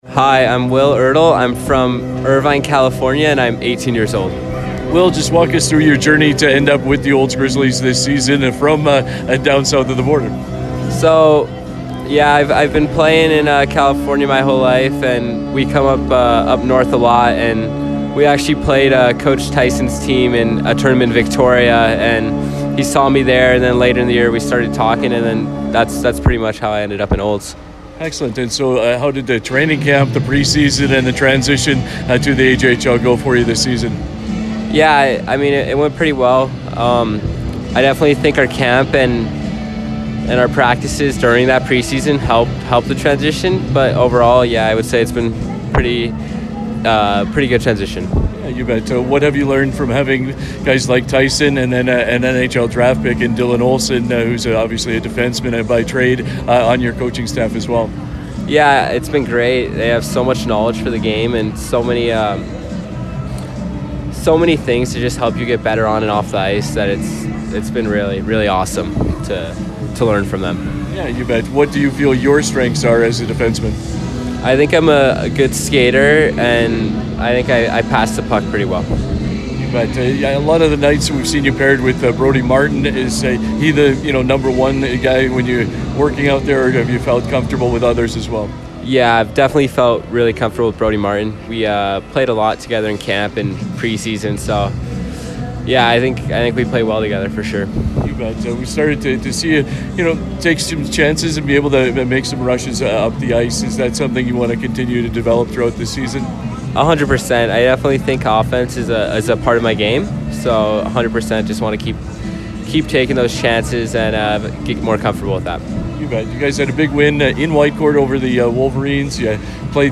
Prior to the game, 96.5 The Ranch spoke with a couple players out of the line up for the Olds Grizzlys.